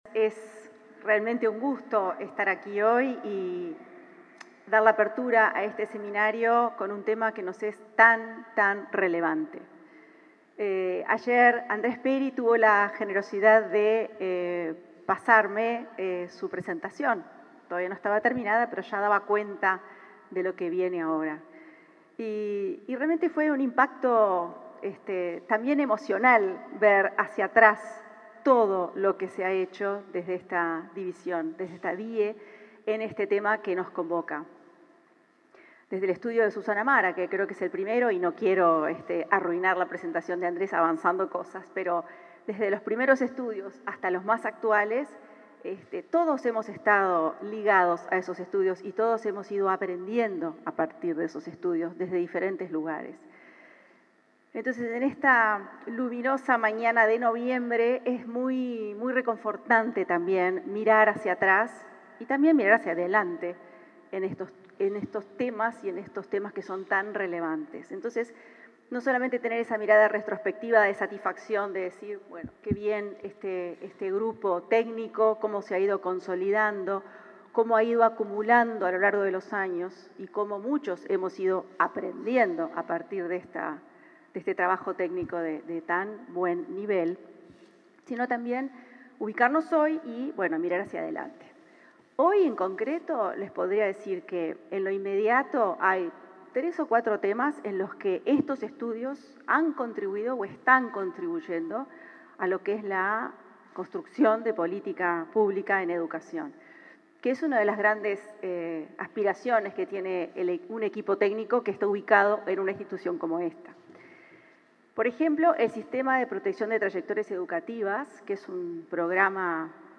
Palabras de autoridades de la ANEP